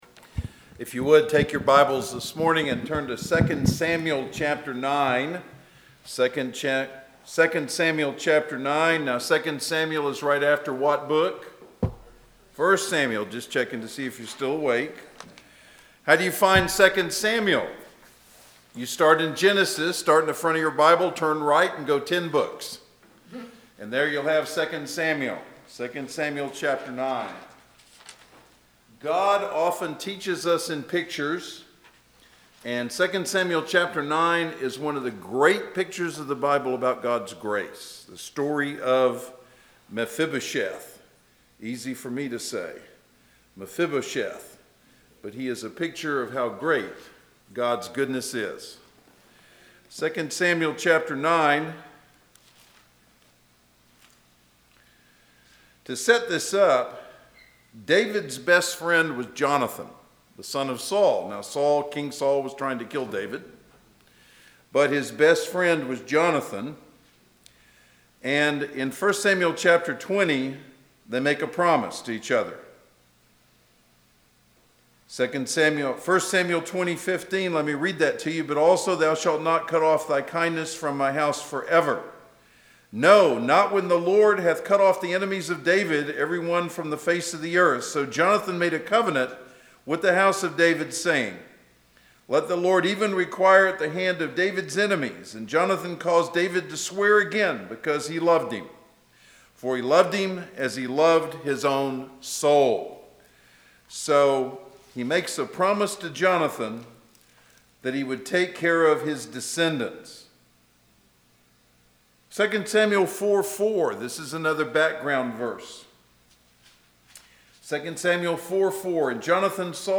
Inman Park Baptist Church SERMONS